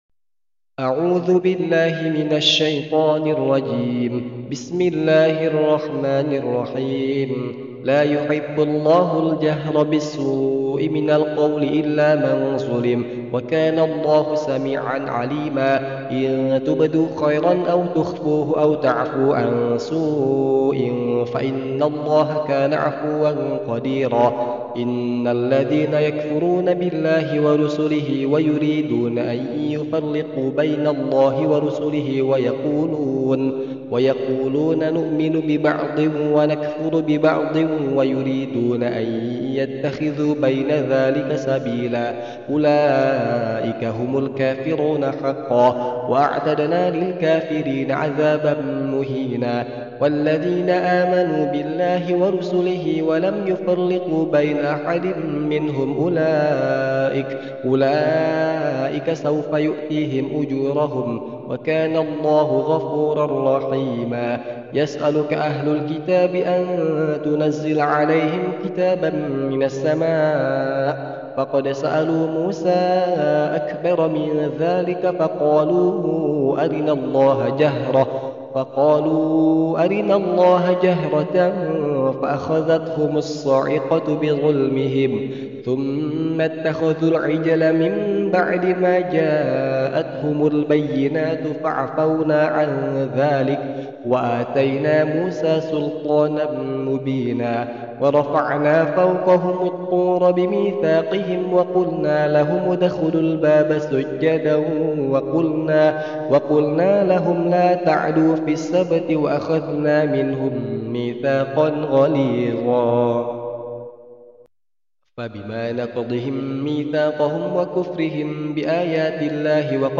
JUZ 6 Murojaah | Tempo Cepat (Bagian 1)